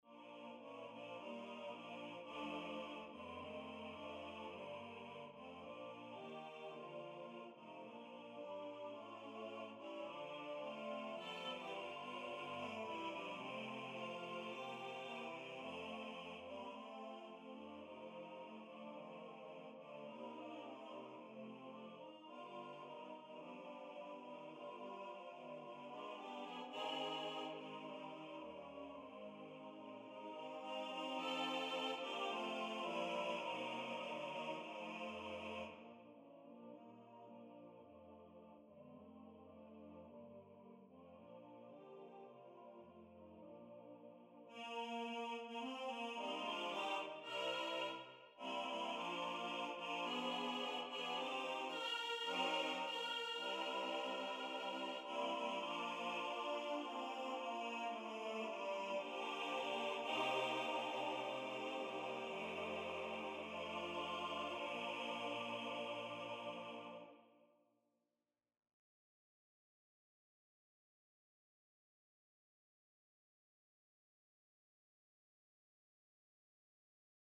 Voices: T1, T2, B1, B2 Instrumentation: a cappella
NotePerformer 4 mp3 Download/Play Audio